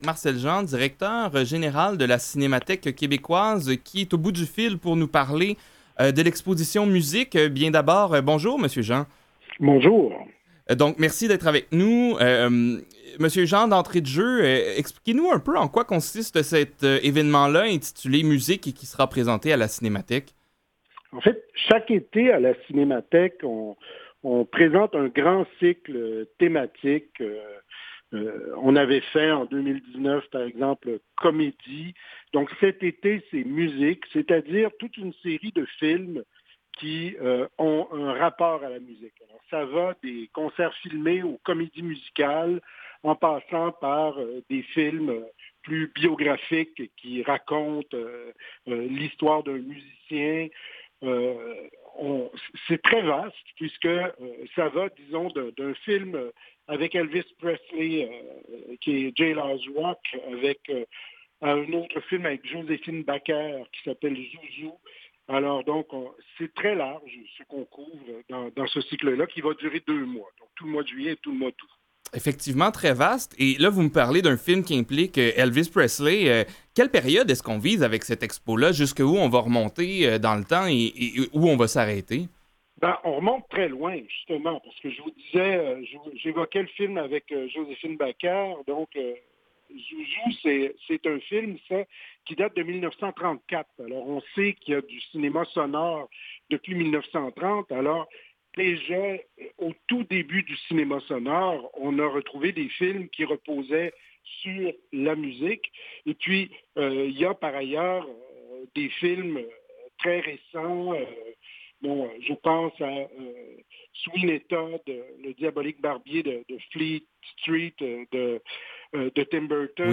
Aux Quotidiens Revue de presse et entrevues du 30 juin 2021